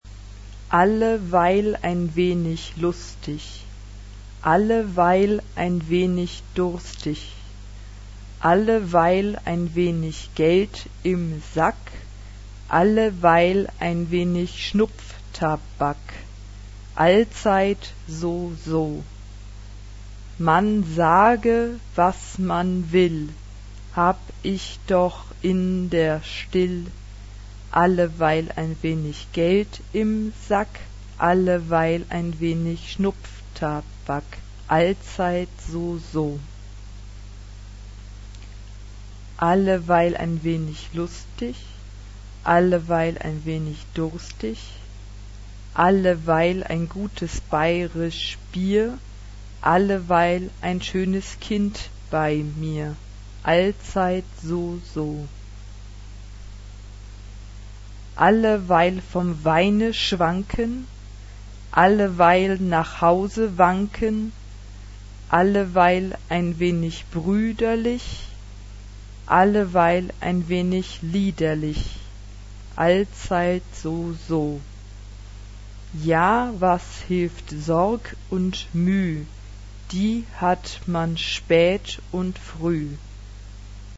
Genre-Style-Form: Secular ; Choir
Instrumentation: Piano (1 instrumental part(s))
Tonality: F major